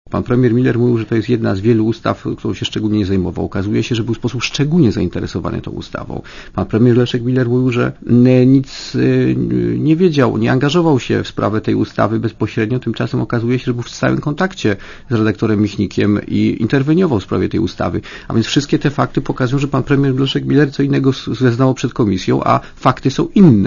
Premier musi jeszcze raz zeznawać przed komisją śledczą – powiedział Radiu Zet członek komisji, poseł Prawa i Sprawiedliwości Zbigniew Ziobro.
Komentarz audio (92Kb)